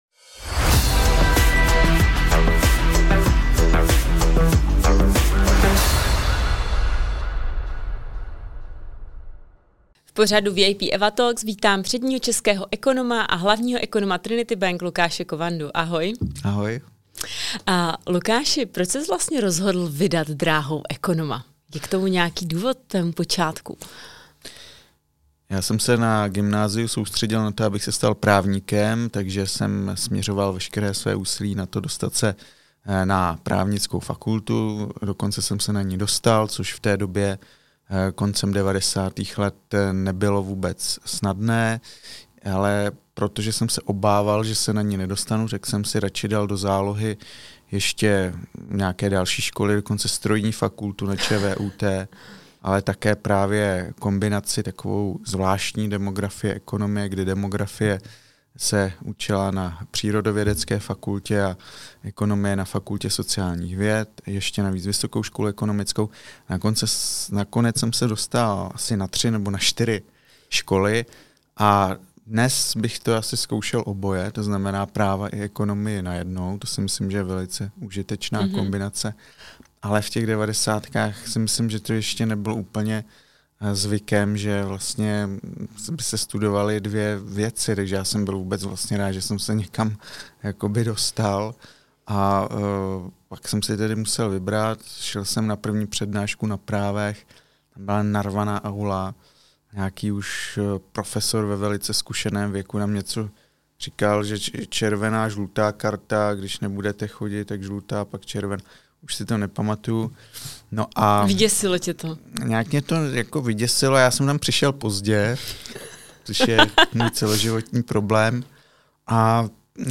A co na sebe v rozhovoru prozradil? Například to, že ve volném čase běhá, nezalekne se ani dlouhých tratí. Zdolal například i newyorský maraton.